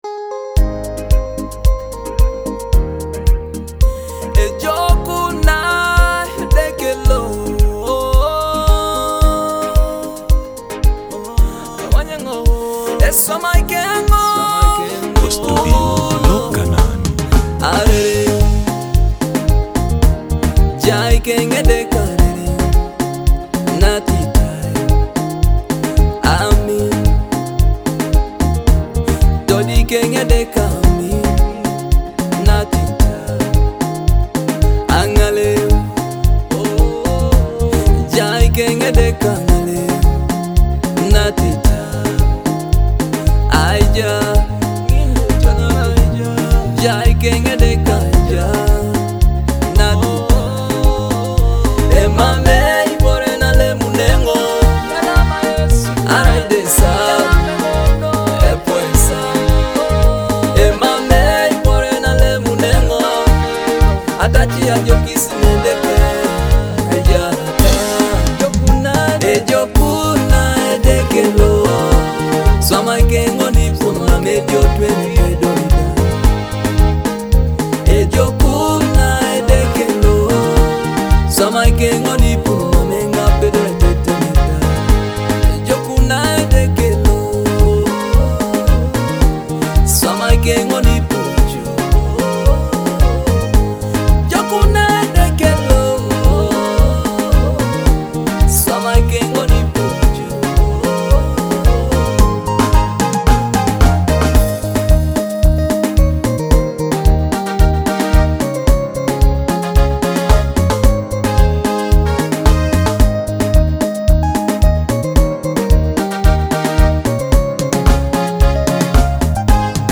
gospel release
soulful sound of gospel music